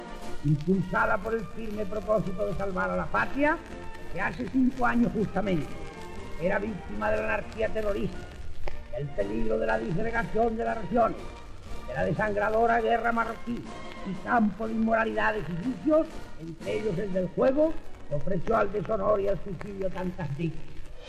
El rei Alfonso XIII justifica, cinc anys després, el desembarcament d'Alhucemas (1925), que va formar part d'una operació militar espanyola combinada amb l'exèrcit francès per acabar amb la rebel·lió de les tribus de beduïns o amazics del Rif (Marroc)
Informatiu
Extret del programa "El sonido de la historia", emès per Radio 5 Todo Noticias el 6 d'octubre de 2012.